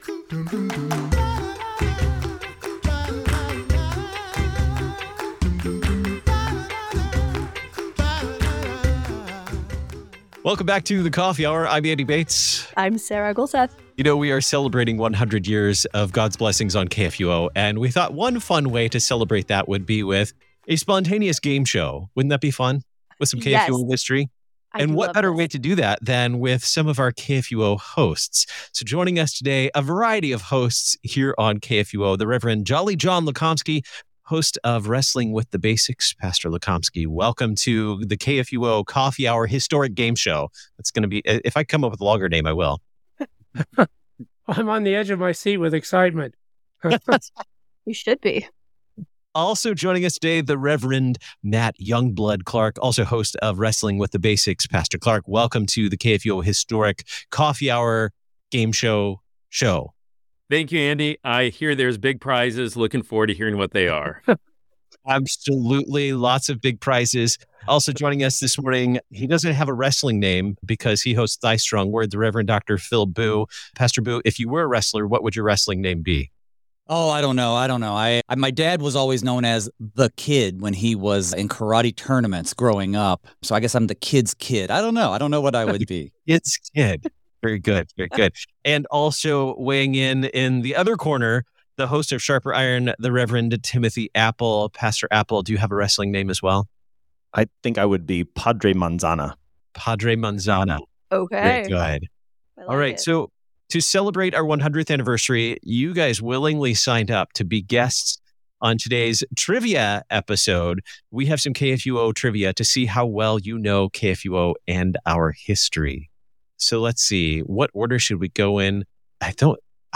It's time for the KFUO History Trivia Game Show! How well do you know KFUO?